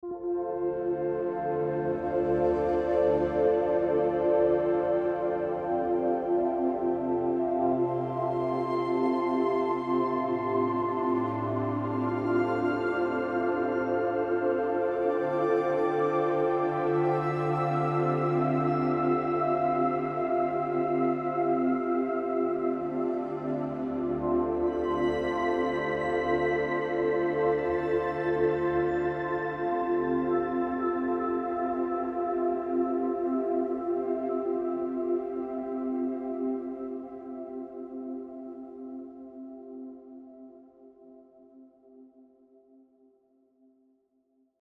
Relaxing music to enjoy the process and stop overthinking
happiness, building